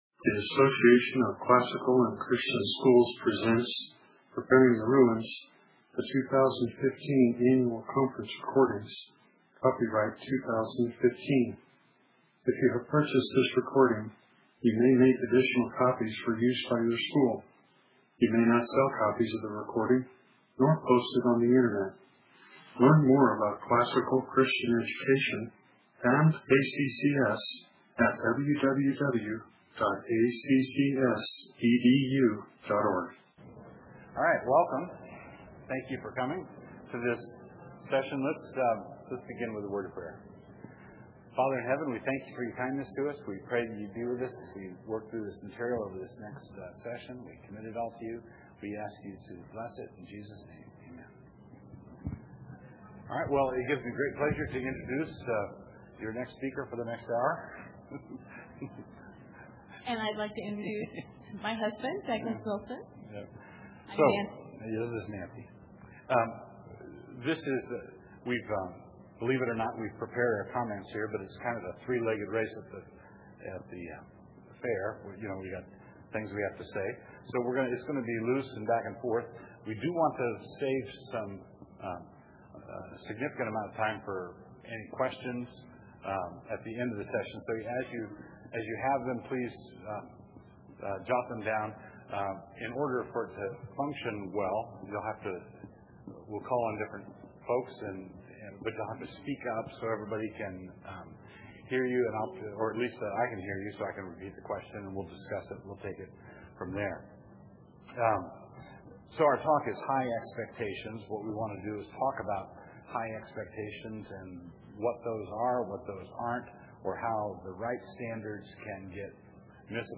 2015 Practicum Talk, 1:02:58, All Grade Levels, General Classroom, Virtue, Character, Discipline